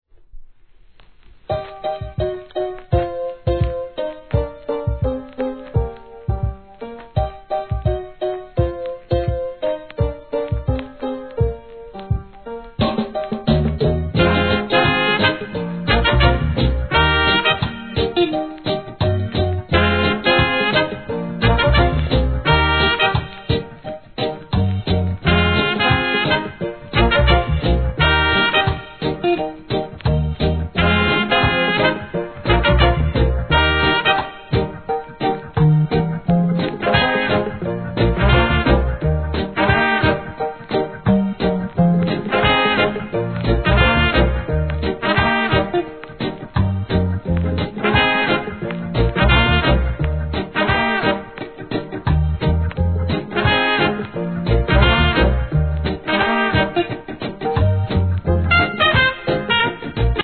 REGGAE
1971年好inst.物!!